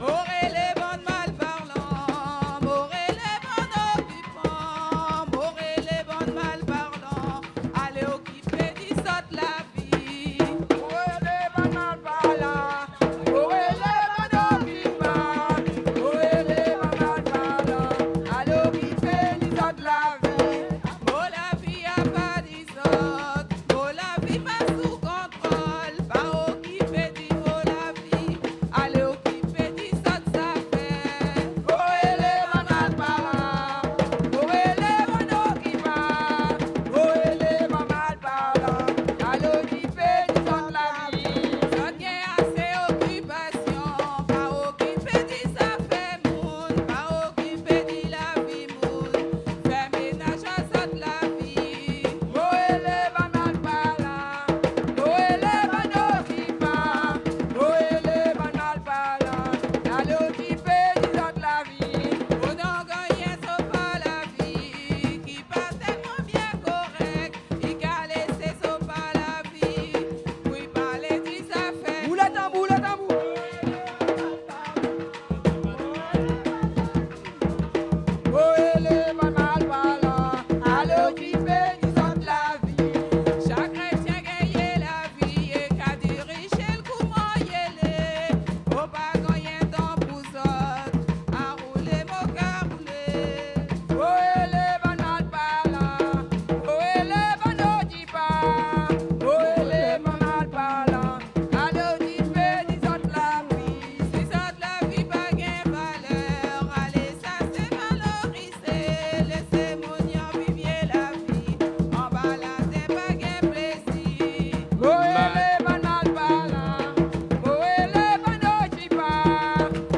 Soirée Mémorial
danse : kasékò (créole)
Pièce musicale inédite